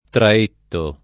vai all'elenco alfabetico delle voci ingrandisci il carattere 100% rimpicciolisci il carattere stampa invia tramite posta elettronica codividi su Facebook Traetto [ tra % tto ] top. stor. (Lazio) — nome di Minturno fino al 1879 — anche cognome